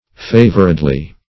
Search Result for " favoredly" : The Collaborative International Dictionary of English v.0.48: Favoredly \Fa"vored*ly\, adv.